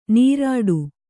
♪ nīrāḍu